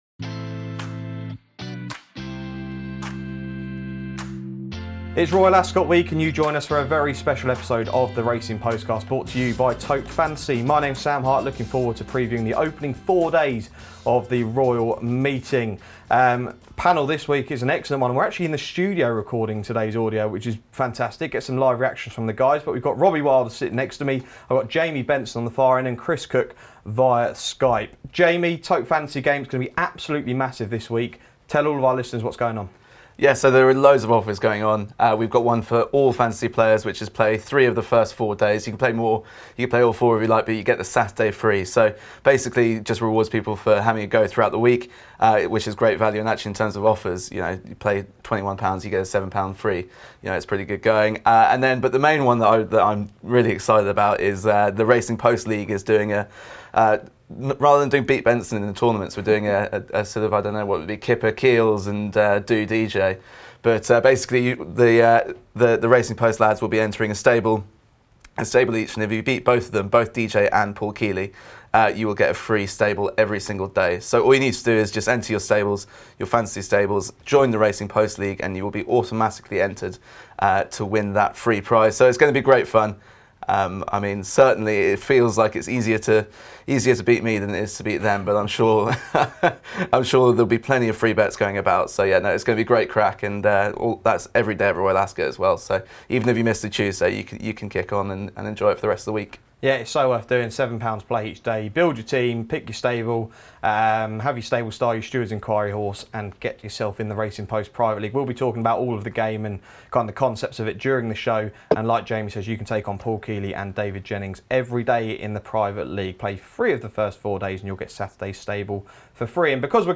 The show starts off with the panel discussing the action on the opening two days, where we have some open-looking races as well as some strong two-year-old races. In part two the team give their thoughts on the Thursday and Friday.